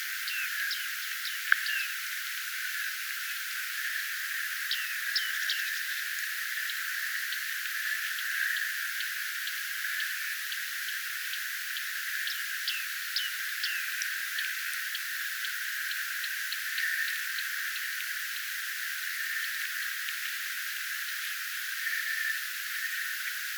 Uudessa haarapääskysen pesäpaikassa.
Läheltä kuului useaan kertaan viiksitimaleiden ääntä.
viiksitimaleiden ääntelyä
viiksitimaleiden_aantelya_ruovikosta.mp3